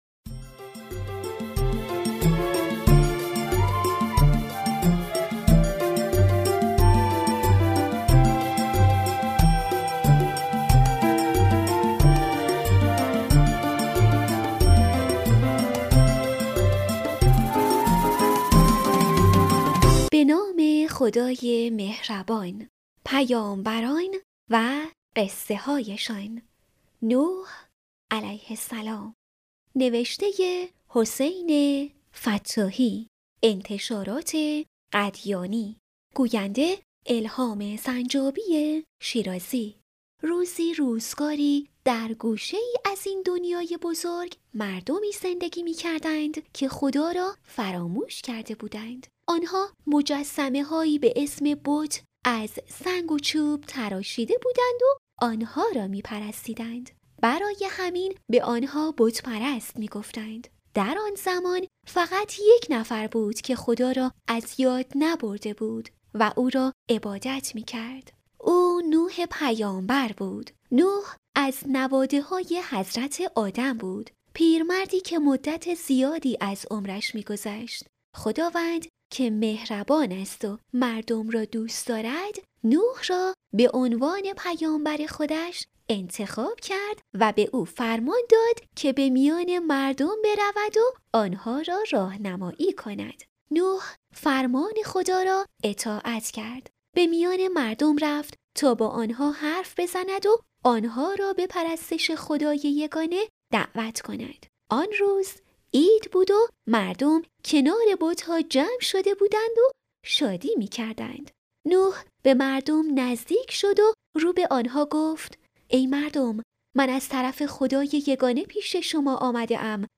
دانلود صوت بفرمایید قصه کتاب صوتی «پیامبران و قصه‌هایشان» این قسمت نوح راوی
# کتاب صوتی # پیامبران الهی # قصه کودک # پادکست # تربیت دینی